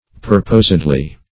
Meaning of purposedly. purposedly synonyms, pronunciation, spelling and more from Free Dictionary.
Search Result for " purposedly" : The Collaborative International Dictionary of English v.0.48: Purposedly \Pur"posed*ly\, adv. In a purposed manner; according to purpose or design; purposely.